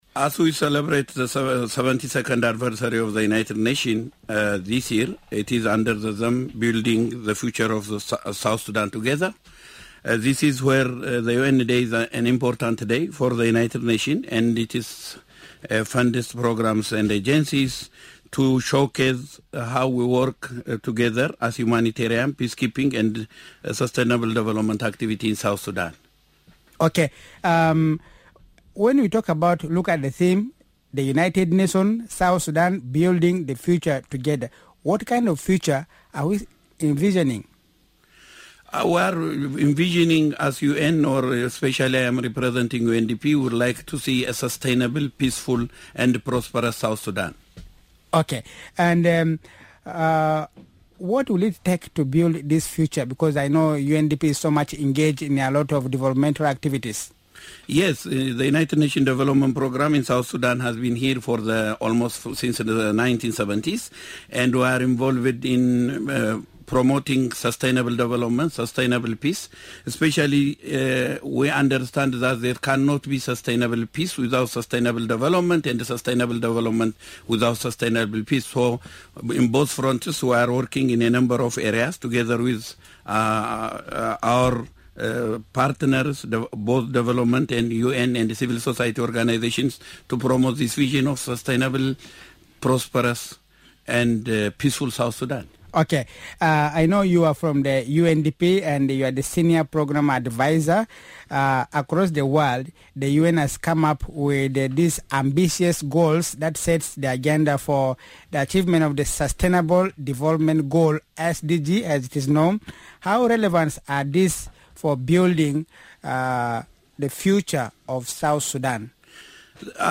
UN Day Discussion - What would it take the UN and South Sudan to Build a future together?